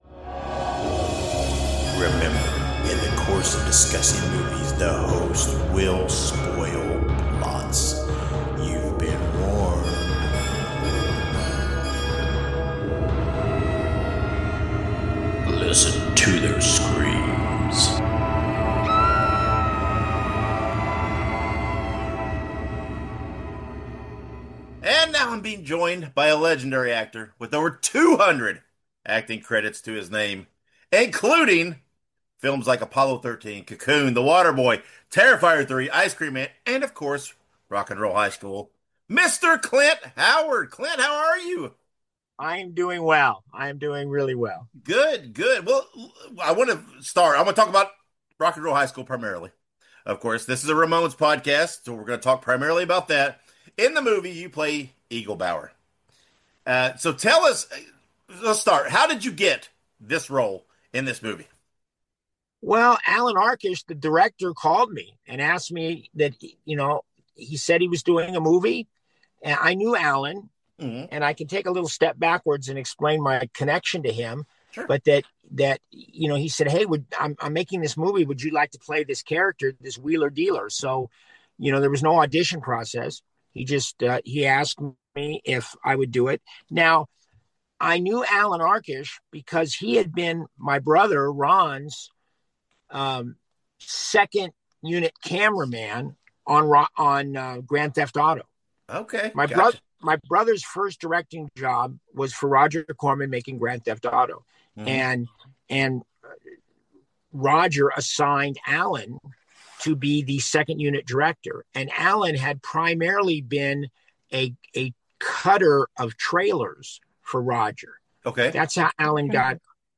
Interview with actor Clint Howard